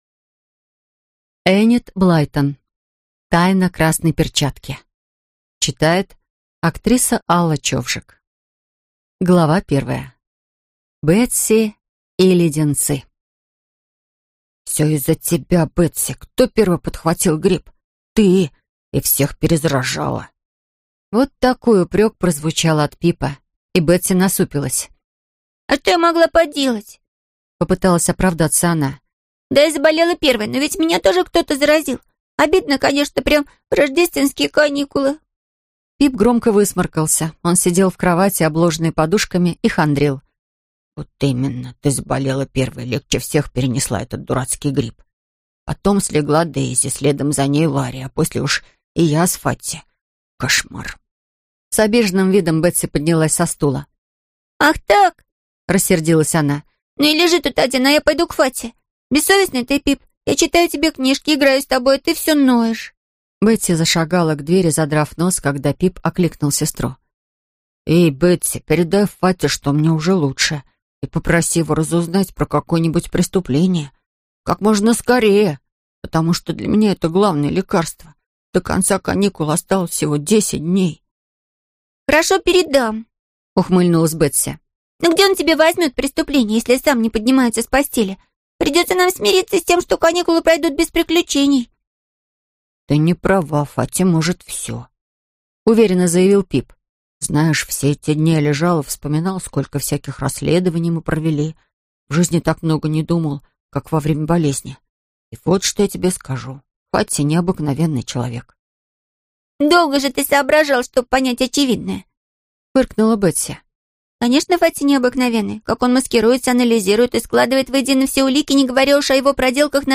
Аудиокнига Тайна красной перчатки | Библиотека аудиокниг
Прослушать и бесплатно скачать фрагмент аудиокниги